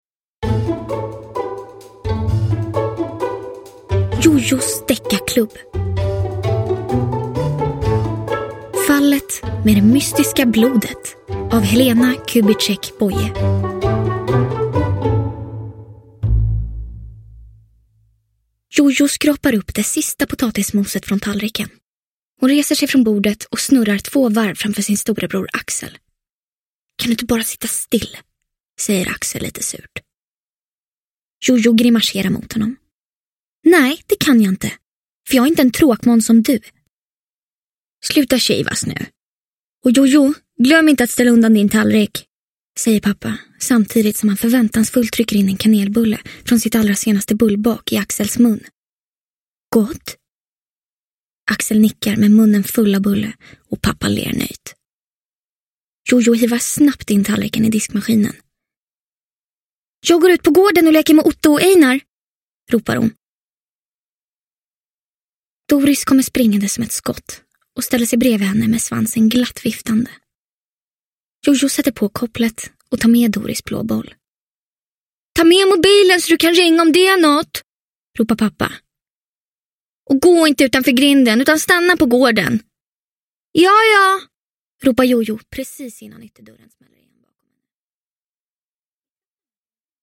Fallet med det mystiska blodet – Ljudbok – Laddas ner